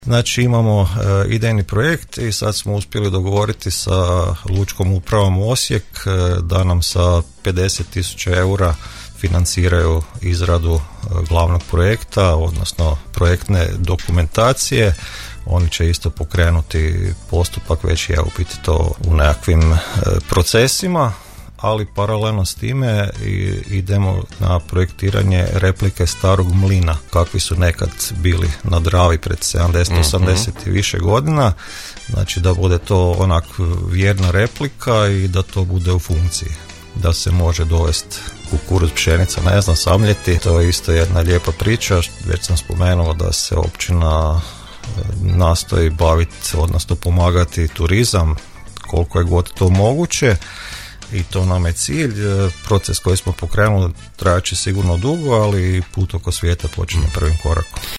Načelnik Maletić u emisiji je govorio i o budućim projektima na području općine, poticajima za mlade koji prolaze vrlo uspješno te o dodjeli uskrsnice za umirovljenike a pojasnio je i najnovije stanje oko projektiranja riječne marine: